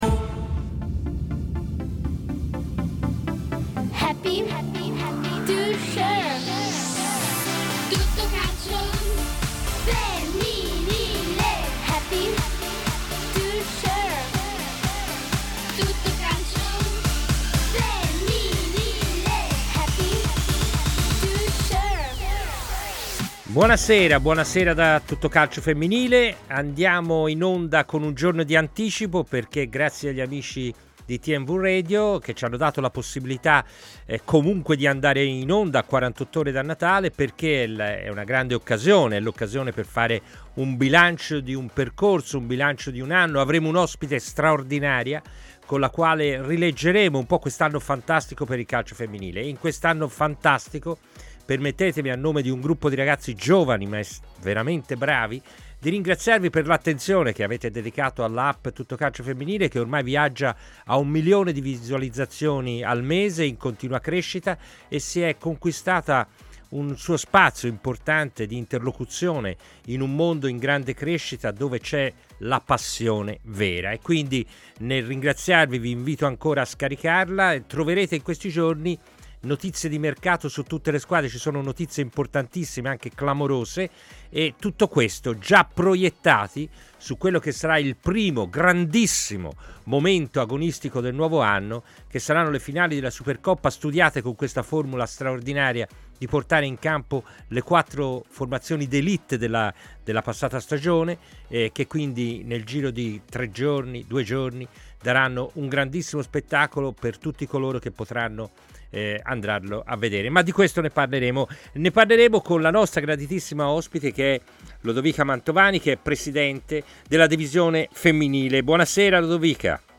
è intervenuta in diretta a TMW Radio durante la trasmissione Tutto Calcio Femminile